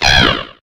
Cri de Bekaglaçon dans sa forme Tête Dégel dans Pokémon HOME.
Cri_0875_Tête_Dégel_HOME.ogg